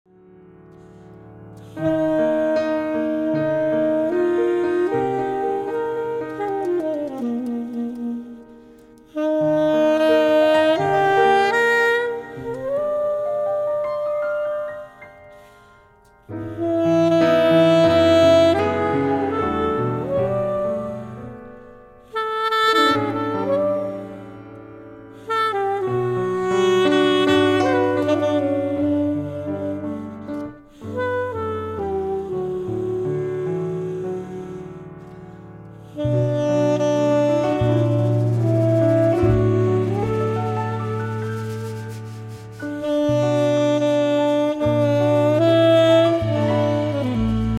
saxes
bass
drums & percussion
pianos and composer